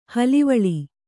♪ halivaḷi